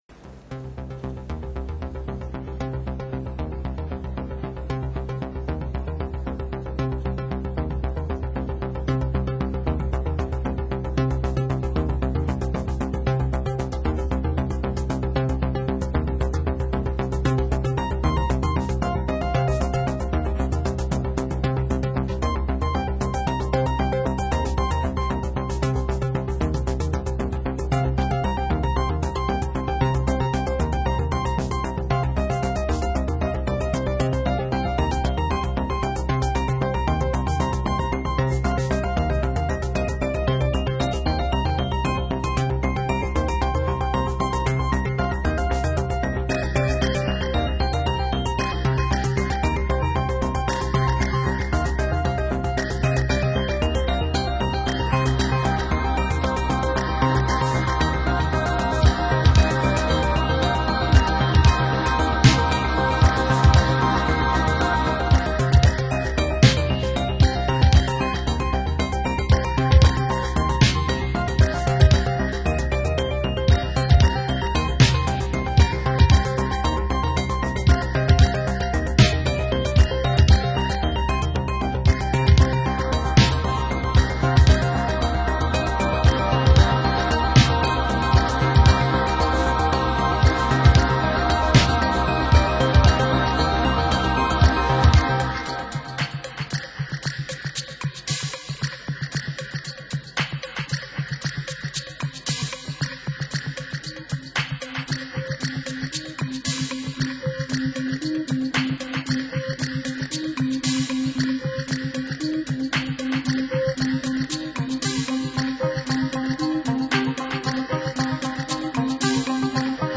在戴上耳机的一瞬间你就 陷入了完全的音乐氛围中，真正的360度环绕声！